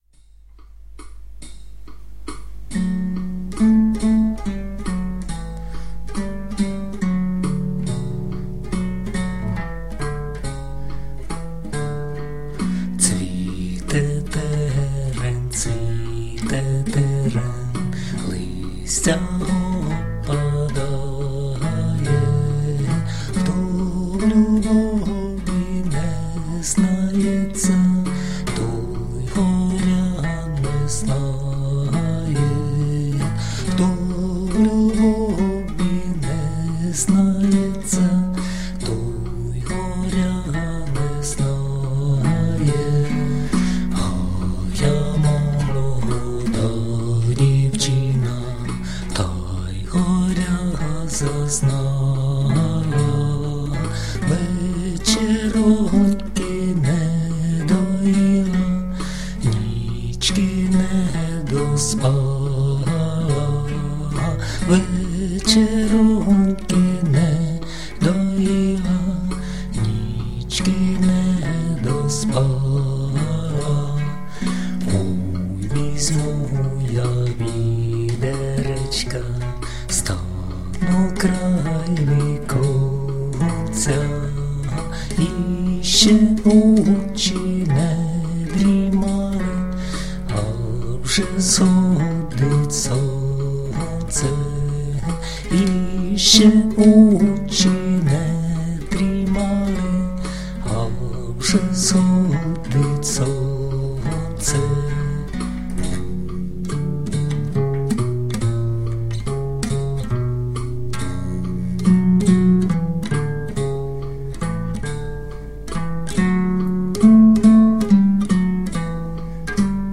../icons/celunoch.jpg   Українська народна пiсня